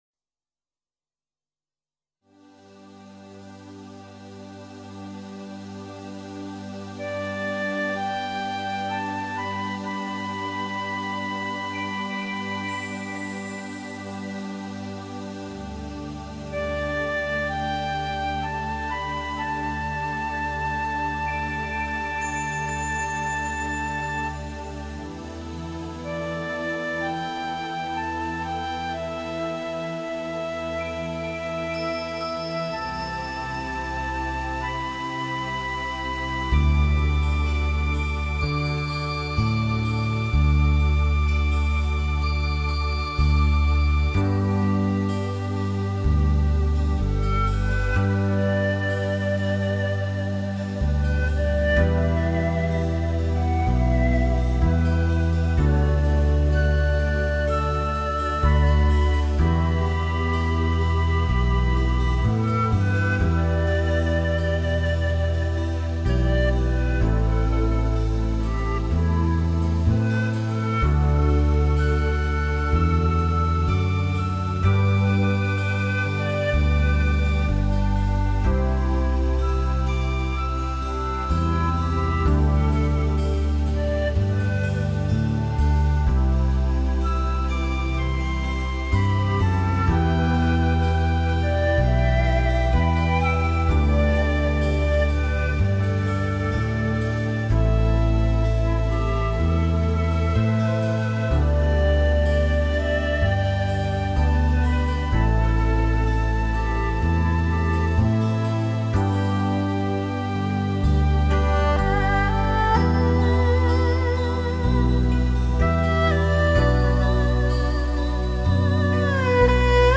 类型：民乐
乐曲用广为人们喜爱的民族乐器以舒缓的节奏，引导聆听者